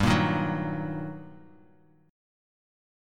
GmM7#5 chord